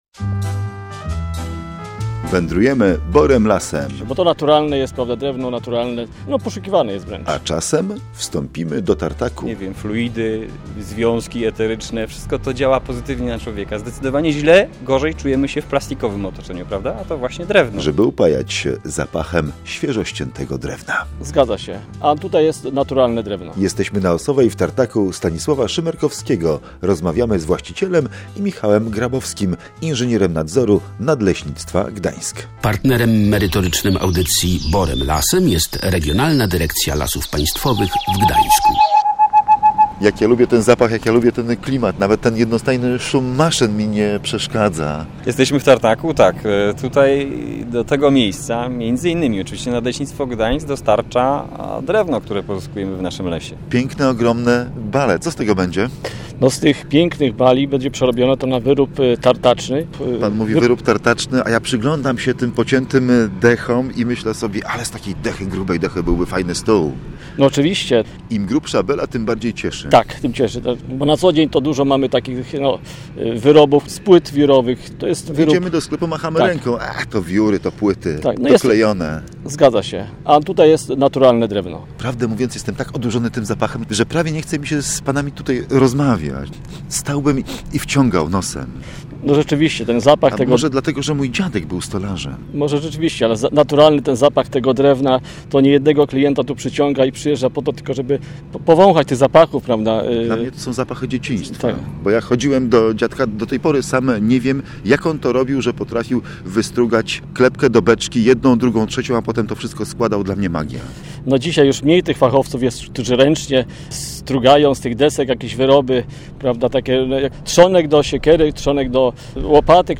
Wyroby ekologiczne to znaczy drewniane – to sprawa oczywista dla każdego leśnika i osób pracujących z drewnem. W tej audycji odwiedzamy tartak